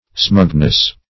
Smugness \Smug"ness\, n.